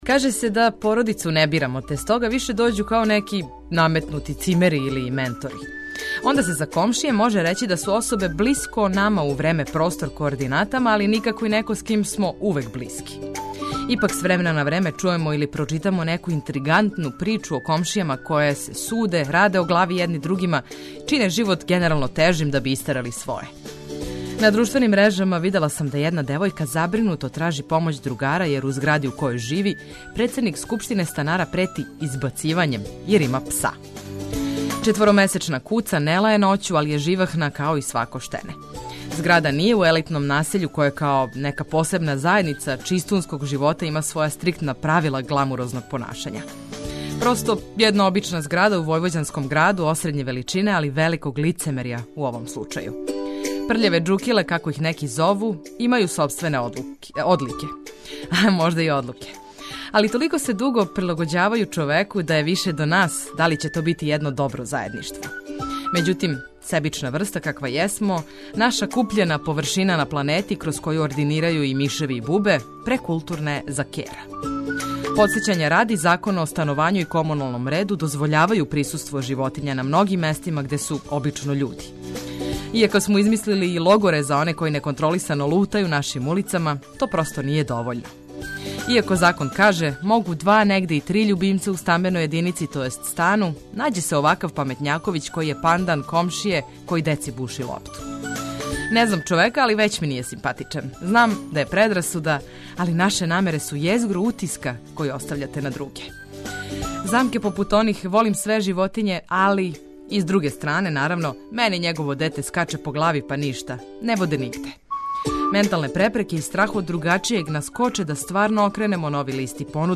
Све важне информације уз омиљену музику су ту, да помогну у организовању дана.